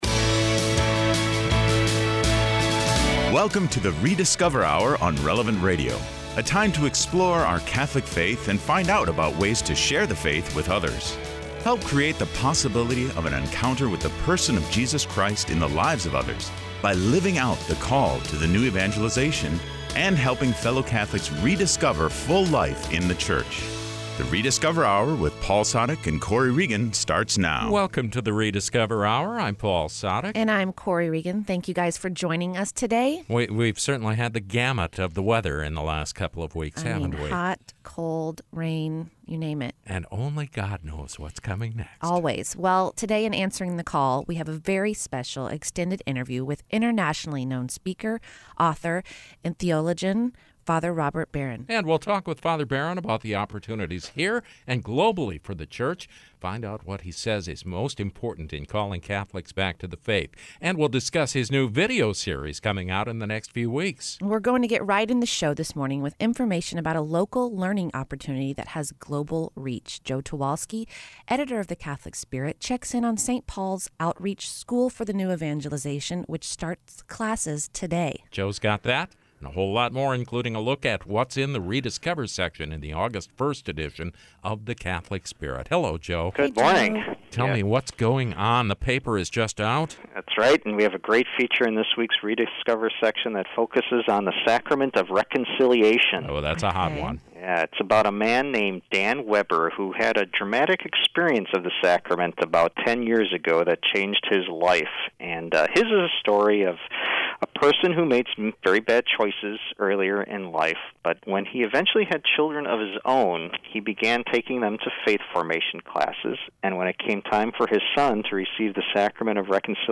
En “Answering the Call” ofrecemos una entrevista ampliada muy especial con el conferencista, autor y teólogo de renombre internacional, Padre Robert Barron . Escuche al padre Barron hablar sobre las oportunidades que se le presentan a la Iglesia, tanto aquí como a nivel mundial. Descubra qué es, según él, lo más importante a la hora de atraer a los católicos de vuelta a la fe.